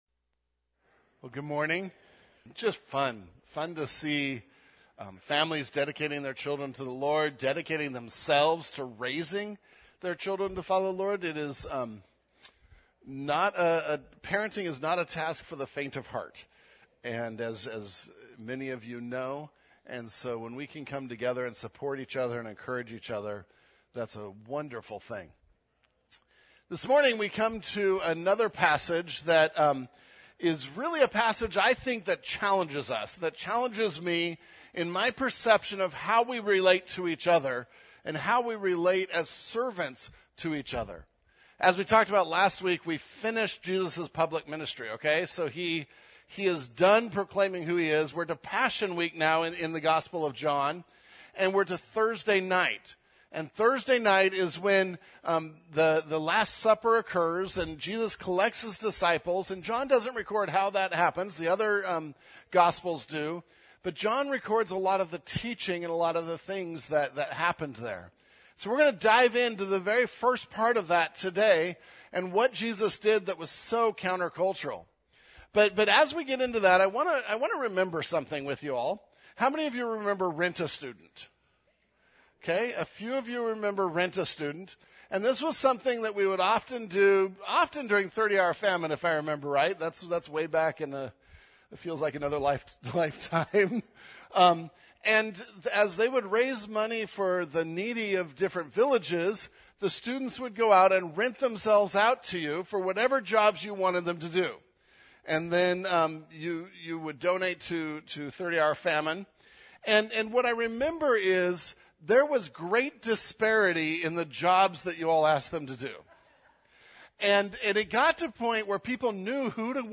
May 18, 2025 Servanthood That Washes (John 13:1-20) MP3 SUBSCRIBE on iTunes(Podcast) Notes Discussion Sermons in this Series Loading Discusson...